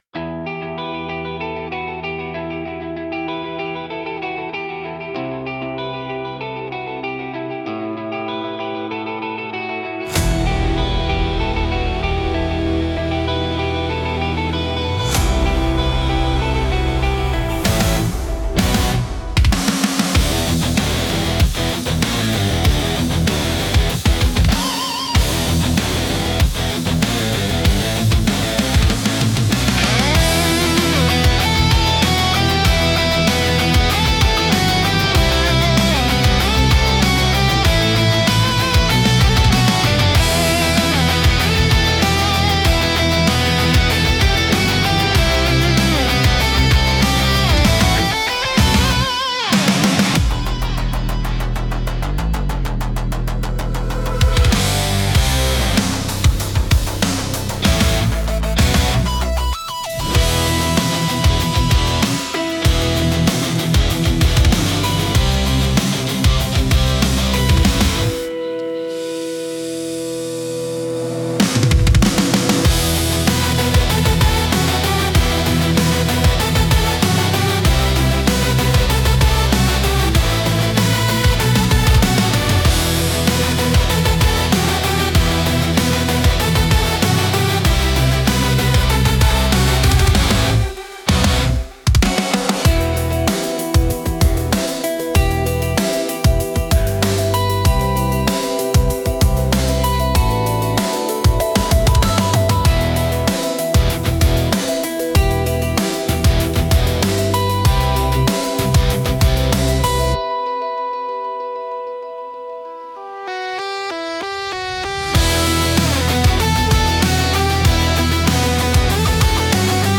песня ai
Instrumental: